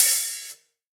UHH_ElectroHatB_Hit-29.wav